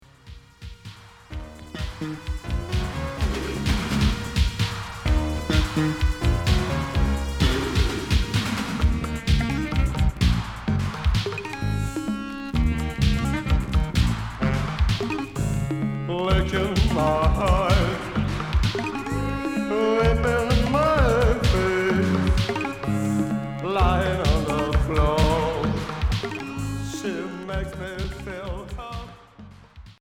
version instrumentale
Cold wave